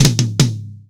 TOM     2C.wav